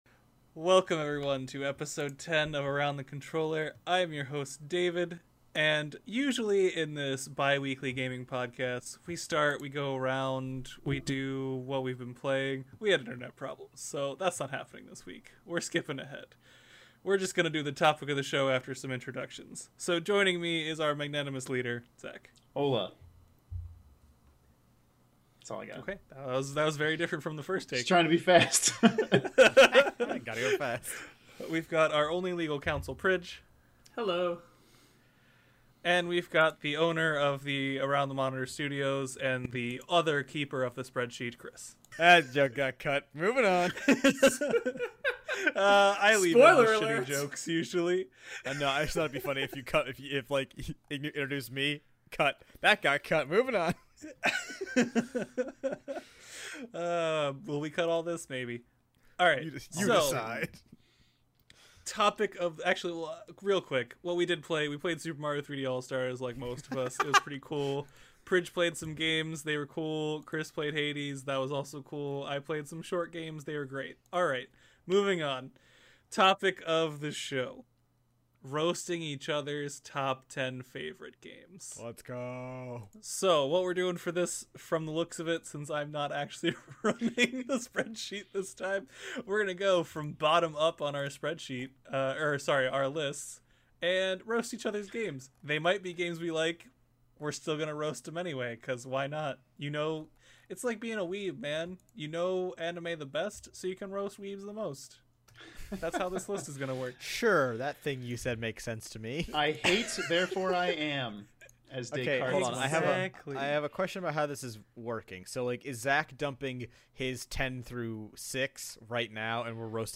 - 01:03 (hyperspeed due to internet issues) Topic of the show - 01:15 Share Facebook X Subscribe Next What Do We Really Expect From Next Gen?